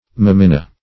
meminna - definition of meminna - synonyms, pronunciation, spelling from Free Dictionary Search Result for " meminna" : The Collaborative International Dictionary of English v.0.48: Meminna \Me*min"na\, n. (Zool.)